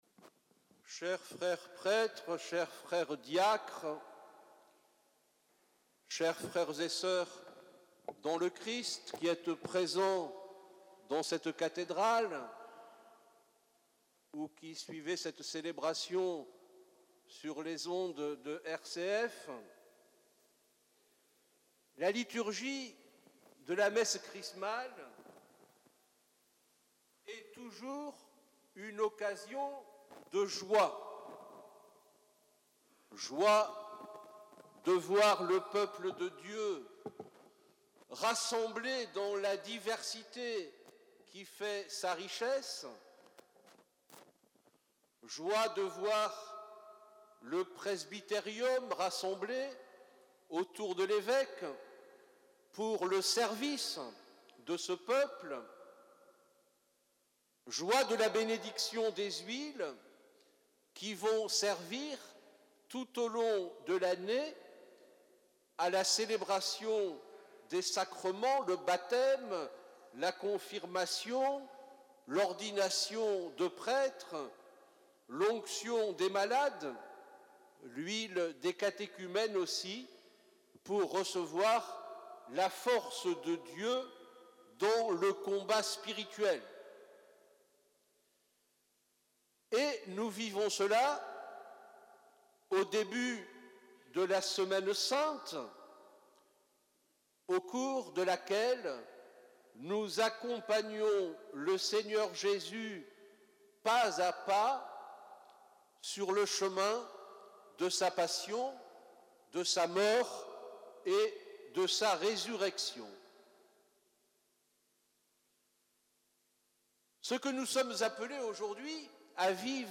Au cours de la messe chrismale, les huiles pour les sacrements, baptême, confirmation et ordination, ainsi que pour les malades, ont été bénies par monseigneur Centène. Les prêtres, plus de deux cents ce mardi 4 avril 2023 en la cathédrale de Vannes, ont renouvelé leurs promesses sacerdotales prononcées le jour de leur ordination.
Homelie-Mgr-Centene-messe-chrismale-2023.mp3